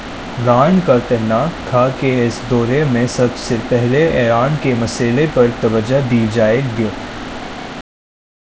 Spoofed_TTS/Speaker_07/266.wav · CSALT/deepfake_detection_dataset_urdu at main